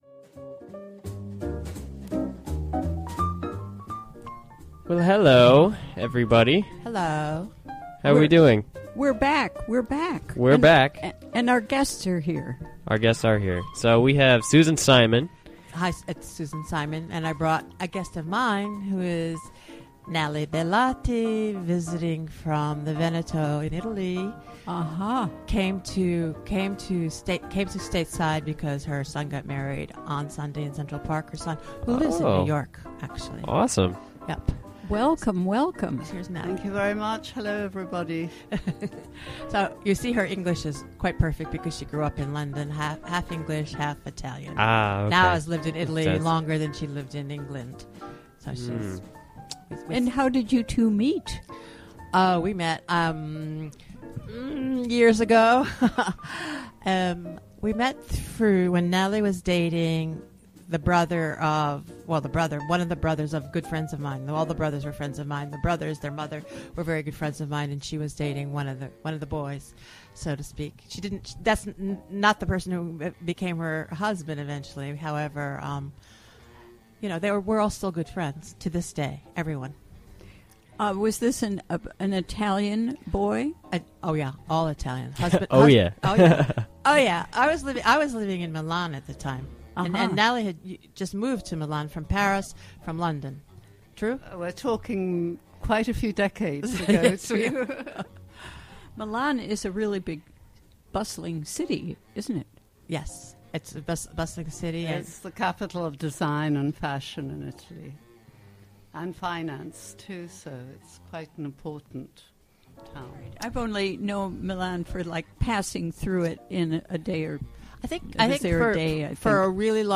Interview recorded during the WGXC Afternoon Show.